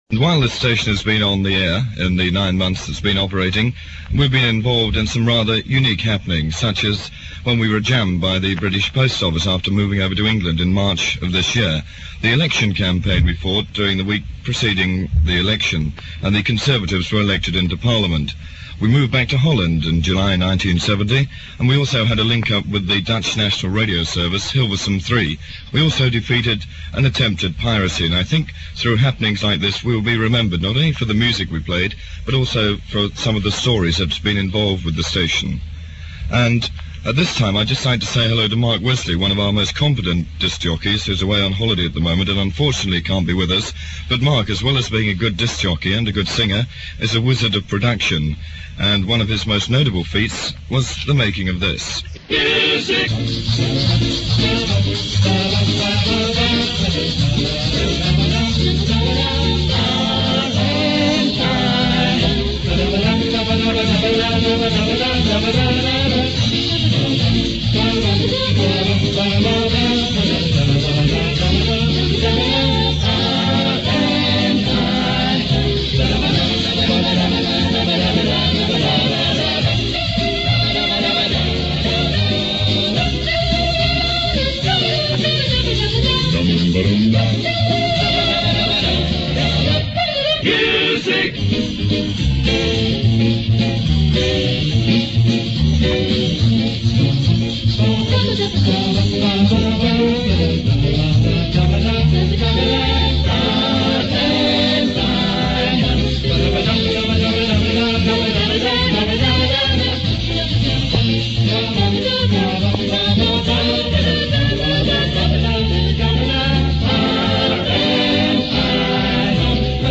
Some of the backroom boys get a chance to say their goodbyes (duration 4 minutes 56 seconds)